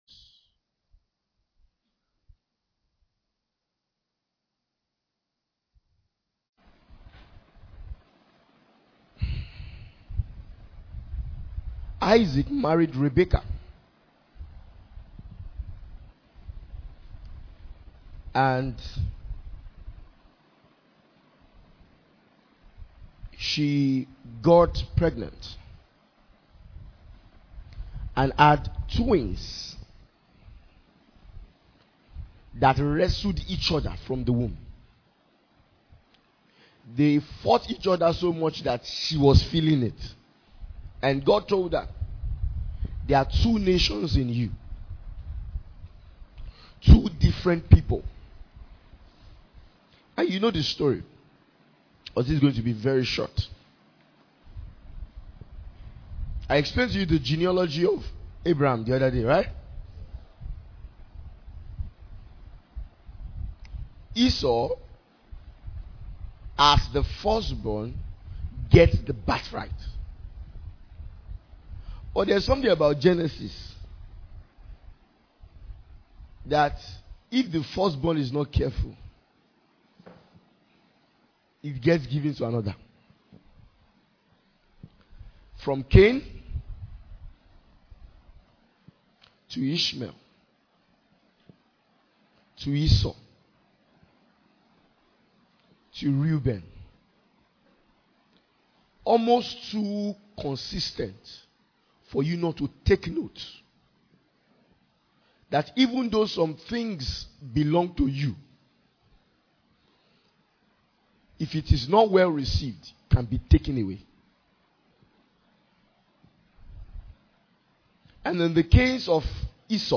Message from our annaul Ministers’ Retreat 2025